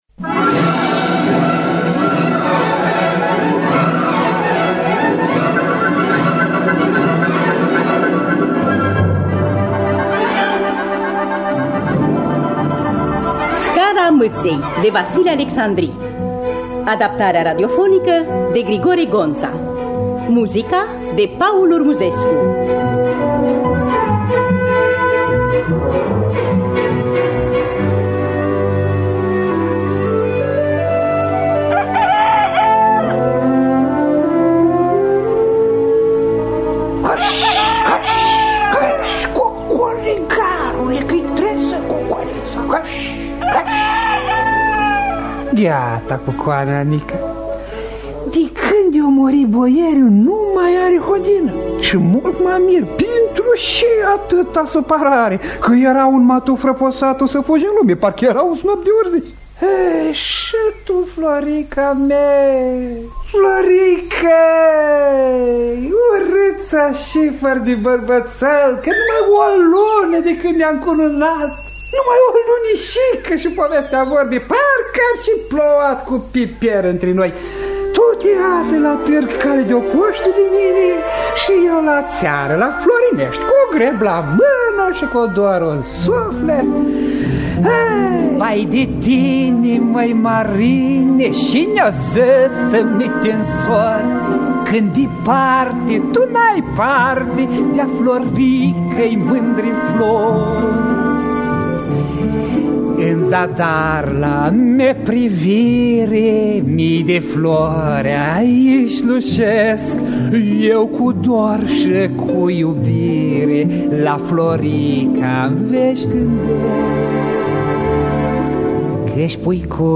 “Scara Mâţei” de Vasile Alecsandri – Teatru Radiofonic Online
Adaptarea radiofonică şi regia artistică